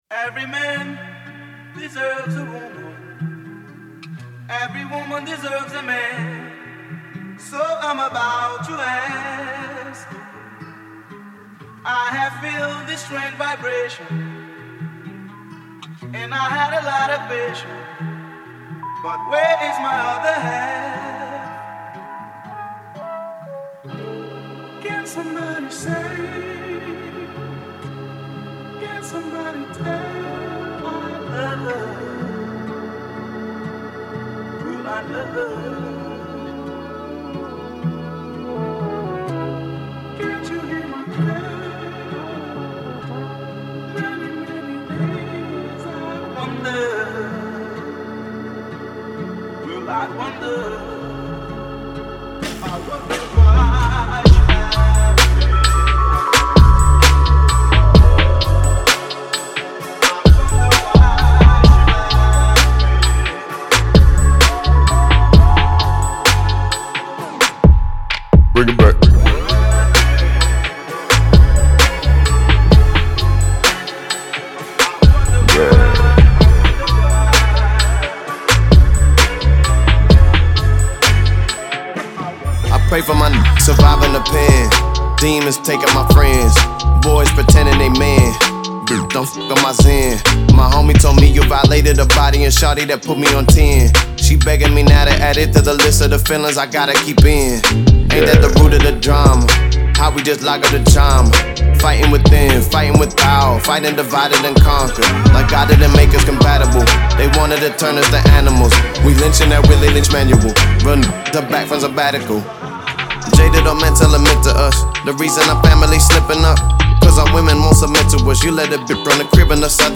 American-Nigerian rapper
American music duo
singer/songwriter